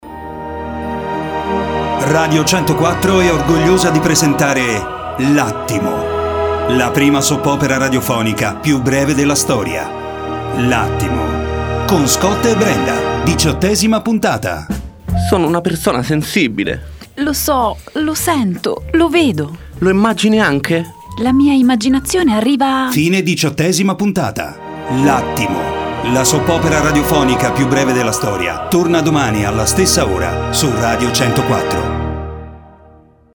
L’Attimo – la Soap opera più breve della storia. Diciottesima puntata